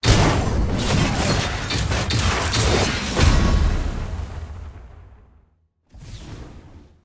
sfx-tier-wings-promotion-to-grandmaster.ogg